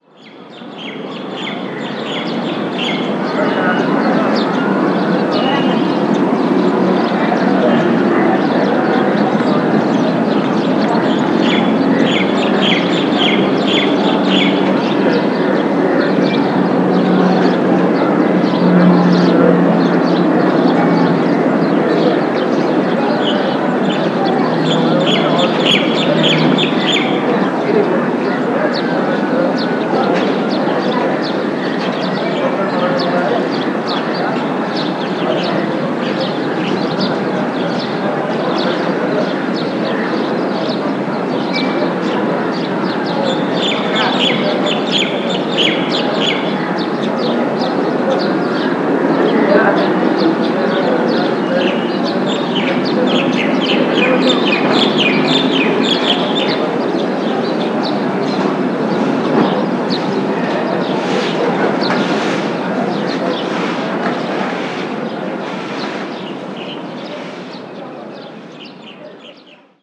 Ambiente de una calle de Katmandú, Nepal
tránsito
pájaro
piar
barullo
gorjear
Sonidos: Animales
Sonidos: Gente
Sonidos: Ciudad